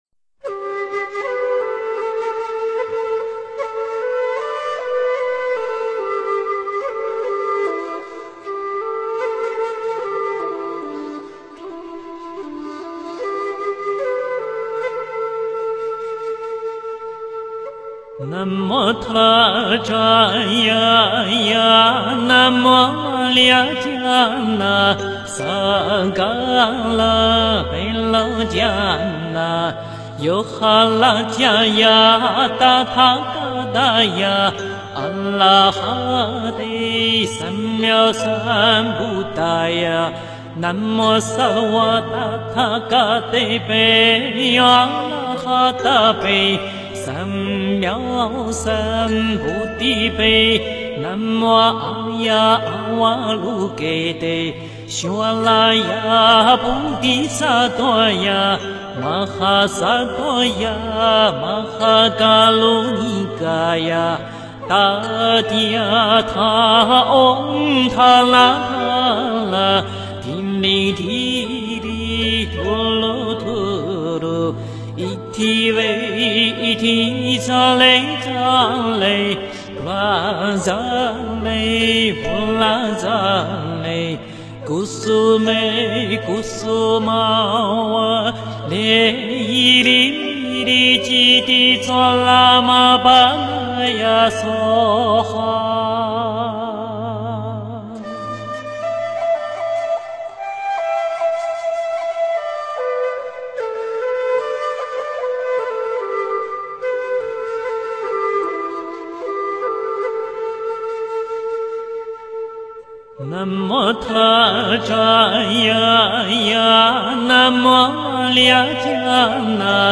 佛音 诵经 佛教音乐 返回列表 上一篇： 半若波罗蜜多心经 下一篇： 吉祥飞扬(准提神咒